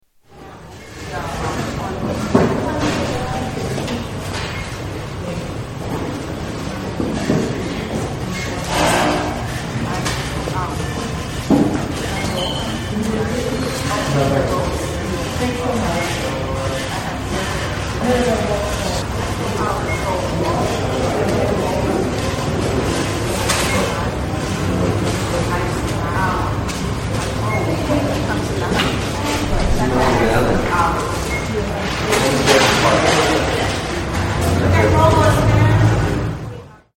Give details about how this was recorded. NYC post office